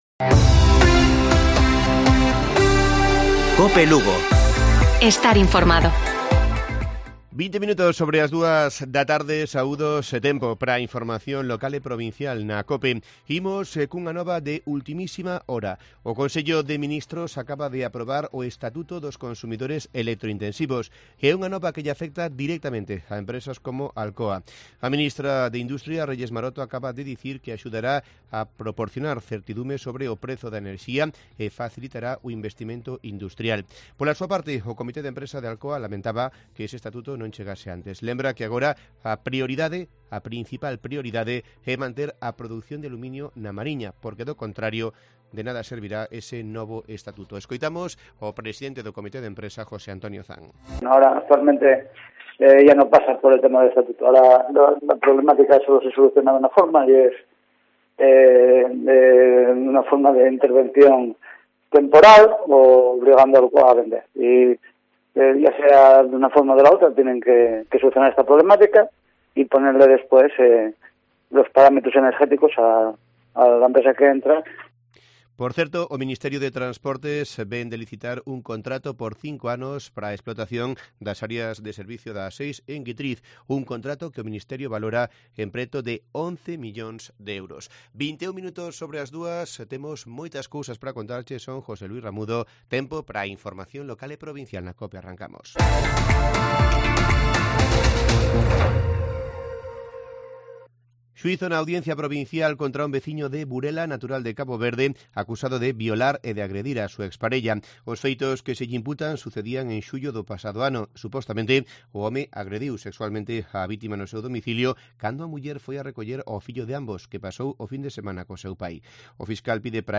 Informativo Provincial de Cope Lugo. 15 de diciembre. 14:20 horas.